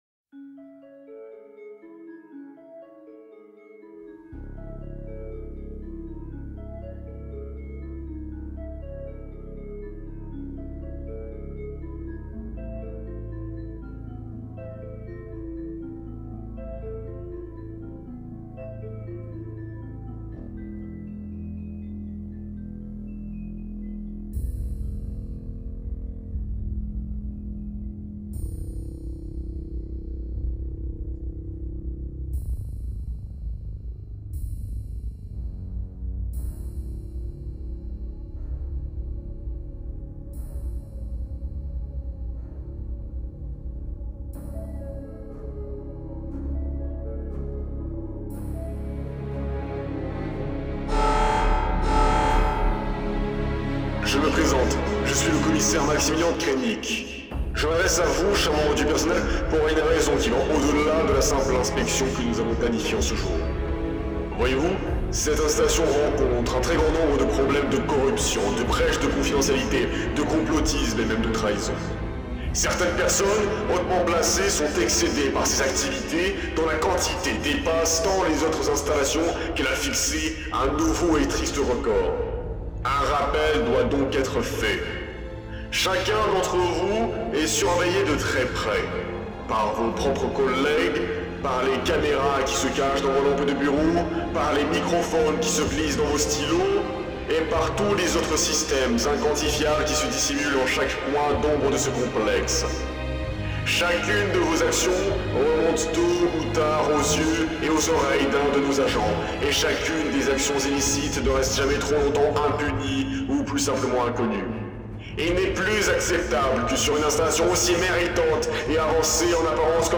Discours DSI.mp3